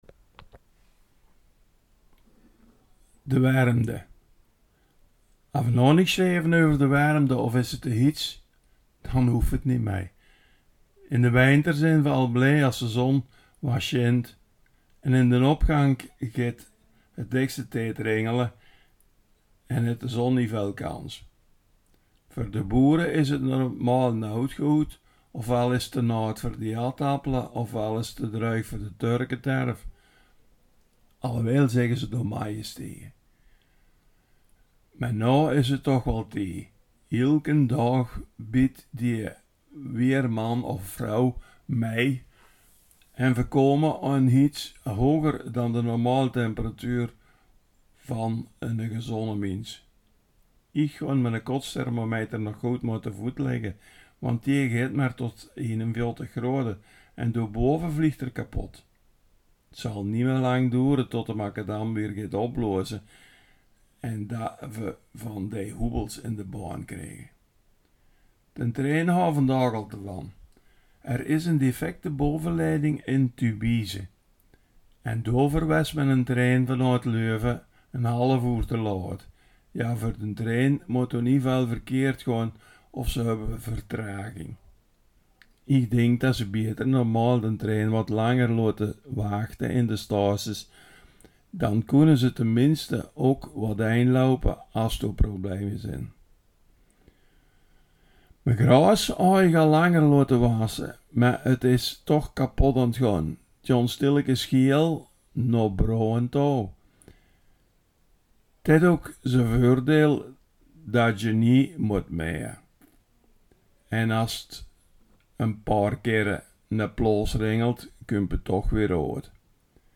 Veldeke Belgisch Limburg | Belgisch-Limburgse dialecten